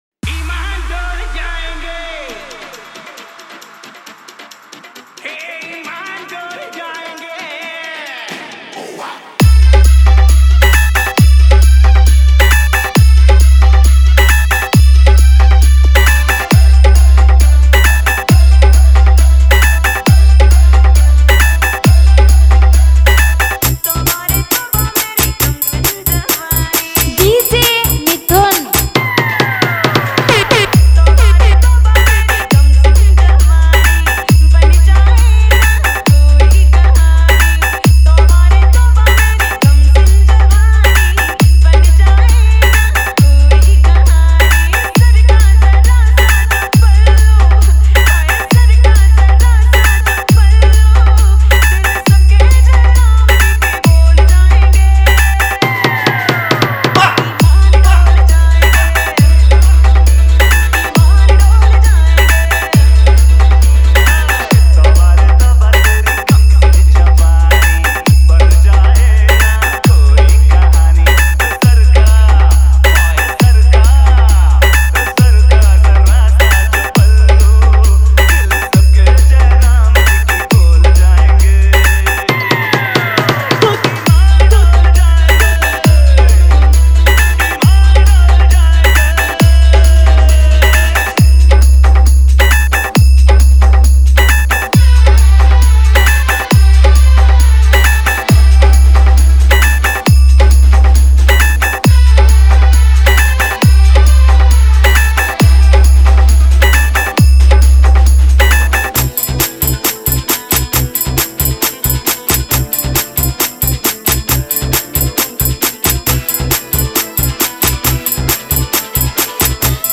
High Power 1 Step Long Tone Humming Mix 2021
Remix